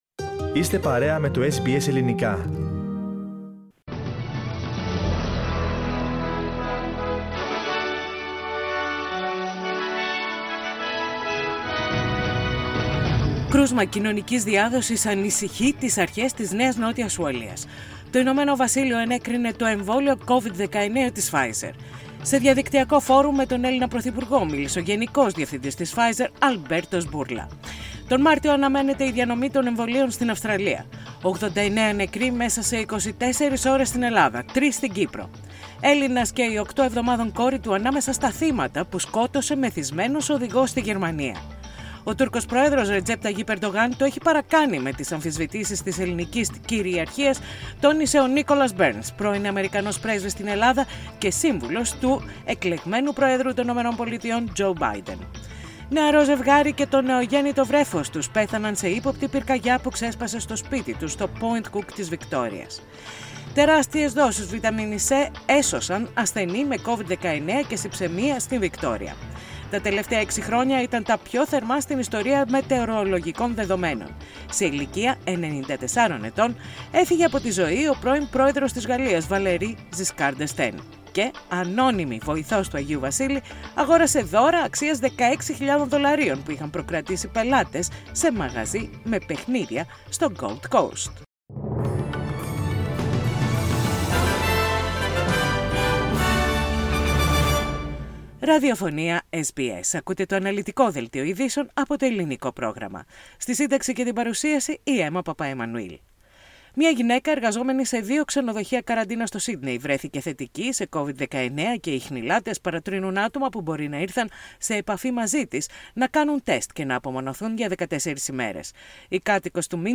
Δελτίο ειδήσεων - Πέμπτη 3.12.20
Οι κυριότερες ειδήσεις της ημέρας, από Αυστραλία, Ελλάδα, Κύπρο και τον κόσμο, όπως τις παρουσίασε το Ελληνικό πρόγραμμα της ραδιοφωνίας SBS.